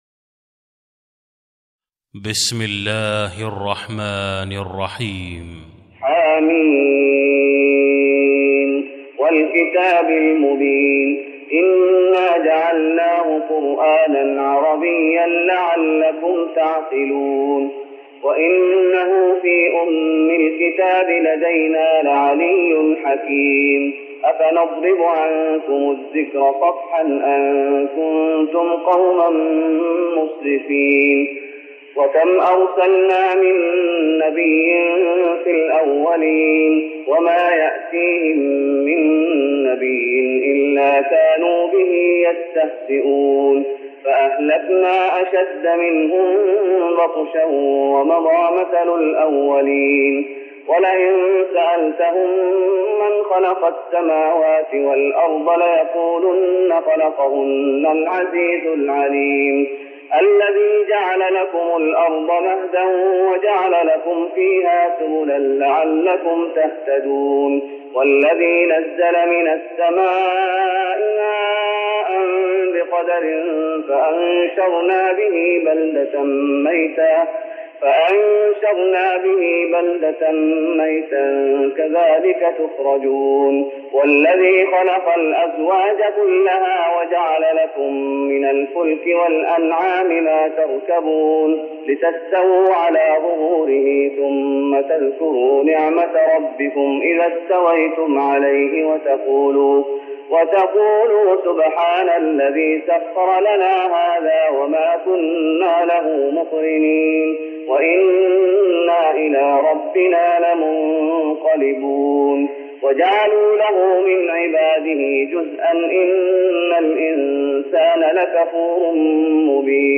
تراويح رمضان 1414هـ من سورة الزخرف Taraweeh Ramadan 1414H from Surah Az-Zukhruf > تراويح الشيخ محمد أيوب بالنبوي 1414 🕌 > التراويح - تلاوات الحرمين